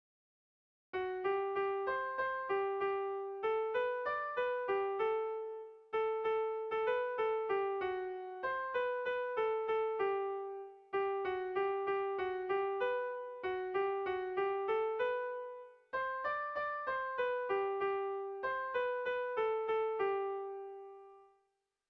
Irrizkoa
Zortziko txikia (hg) / Lau puntuko txikia (ip)
AB1DB2